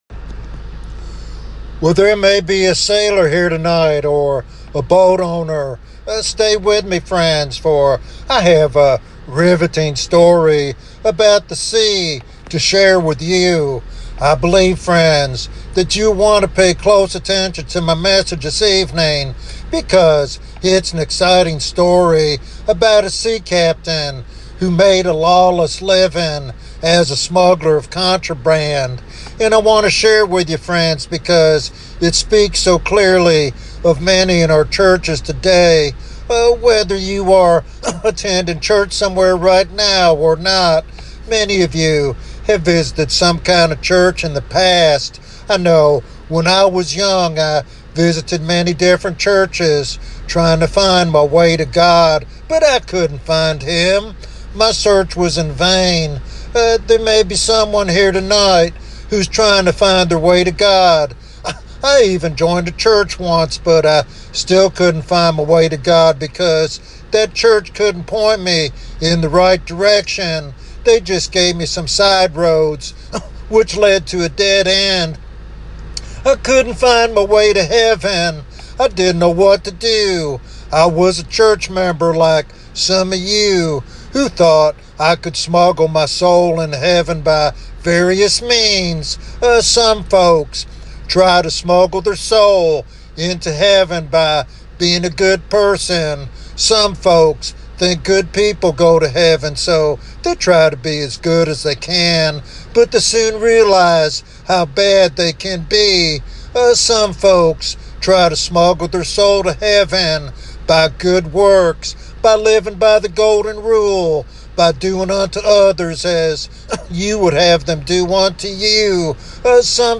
In this compelling evangelistic sermon